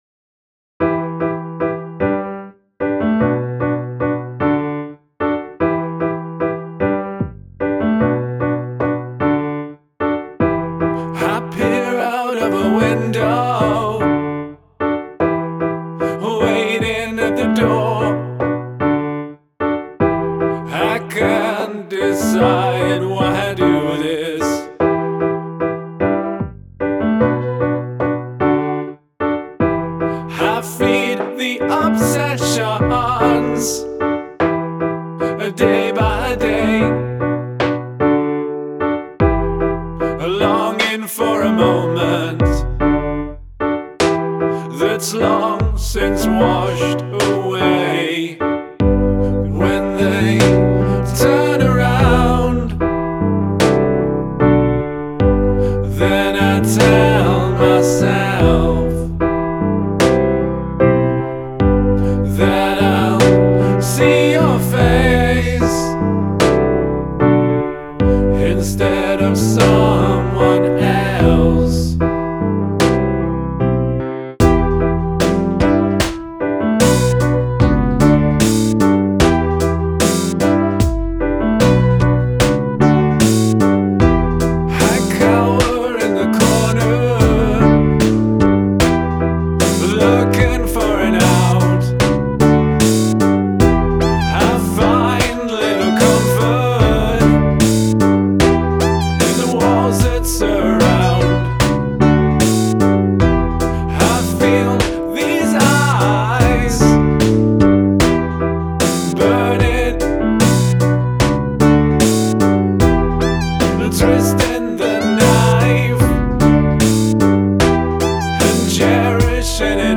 Use of field recording
The piano becomes a bit much after a bit.